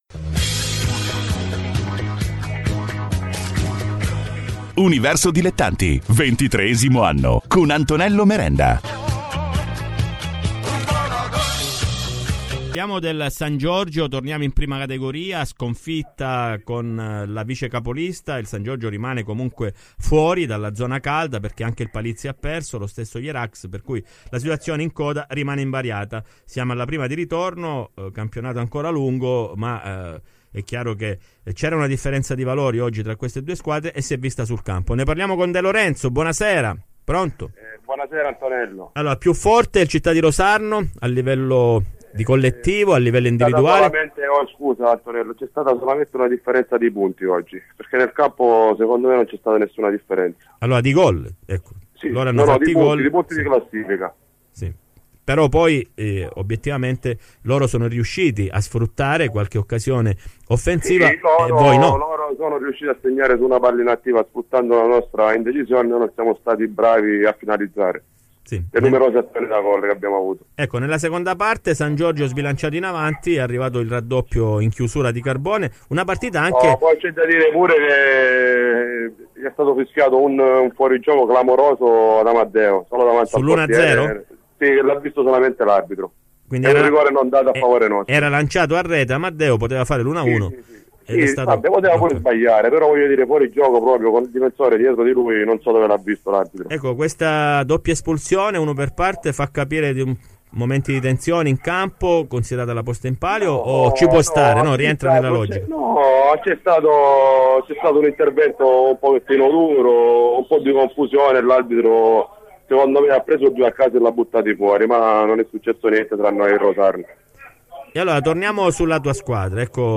Le interviste ai protagonisti di Universo Dilettanti